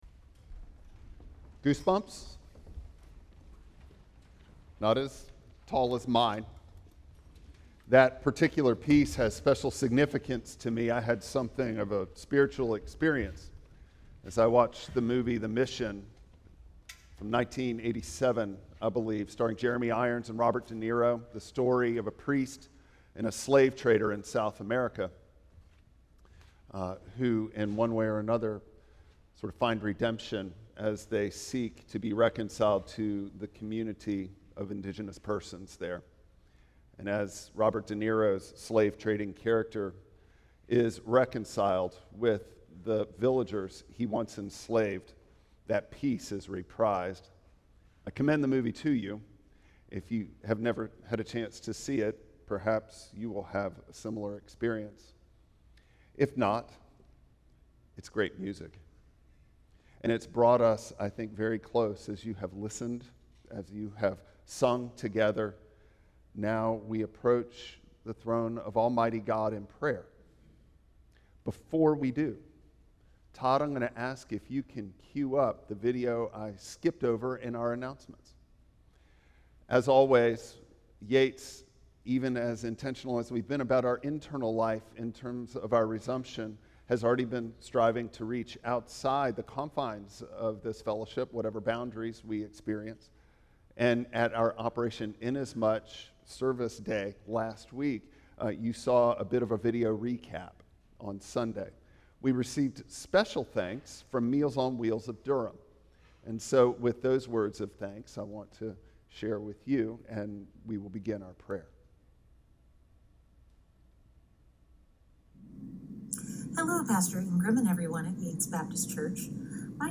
Passage: Nehemiah 5:1-13 Service Type: Traditional Service Bible Text